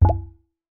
DrumBamboo Pop Notification.wav